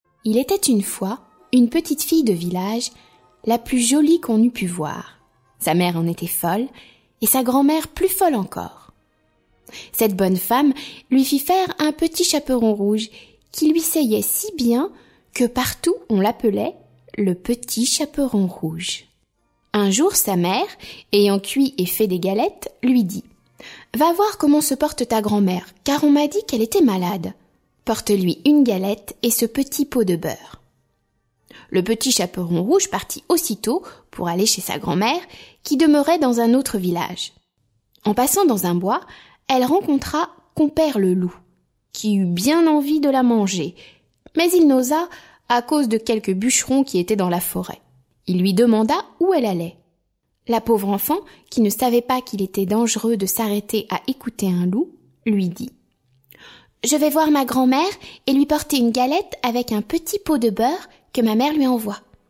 Musique : Rimsky Korsakov